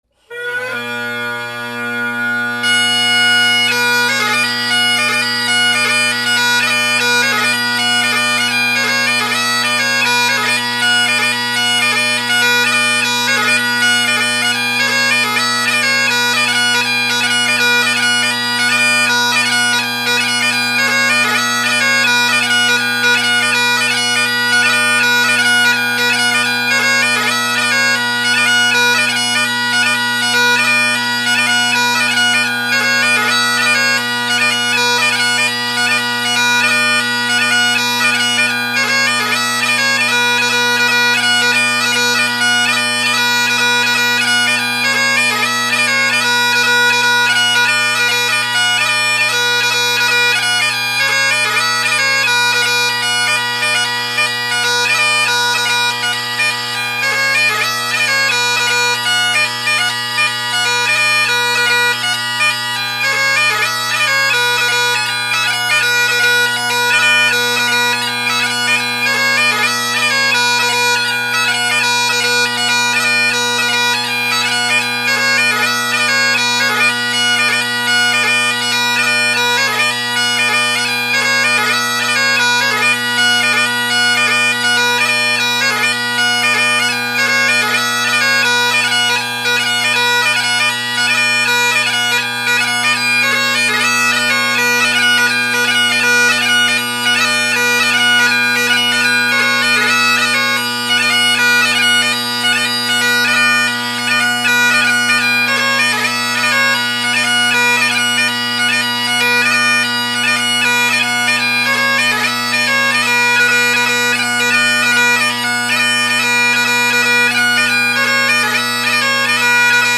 Below are several recordings made over several days of most of my collection of bagpipes.
Jacky Latin (Henderson + X-TREME drone reeds, Shepherd MK3 Classic + Shepherd Bb reed) – mic off to the left (recorded 2019-08-20)